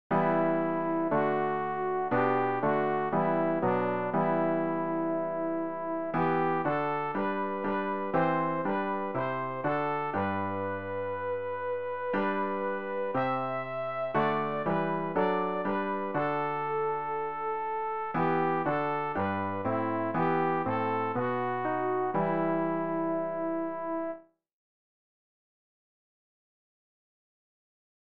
rg-795-sonne-der-gerechtigkeit-sopran.mp3